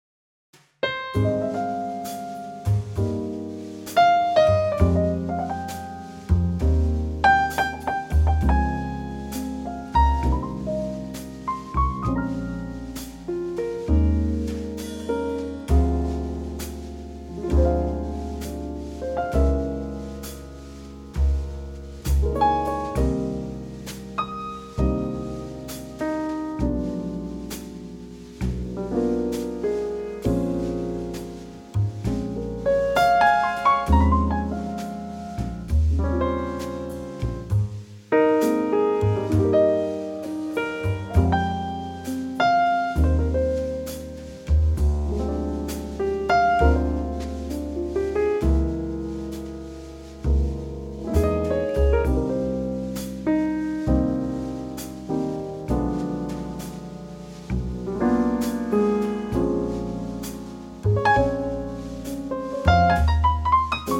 key - Ab - vocal range - Bb to Db